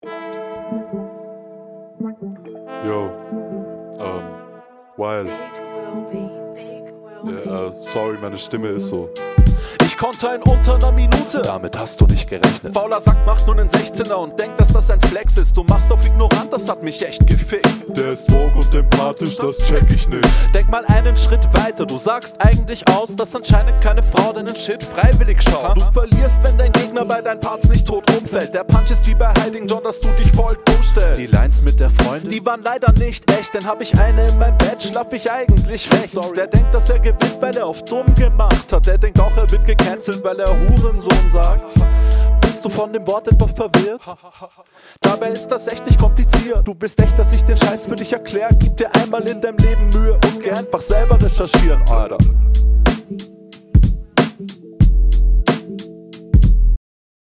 Alter ist das tight geflowt, ihr beide habt euch echt mega entwickelt, stimmeinsatz ist mega …
mische klingt iwie bisschen sehr sehr trocken. passt mMn leider nicht so auf dem beat …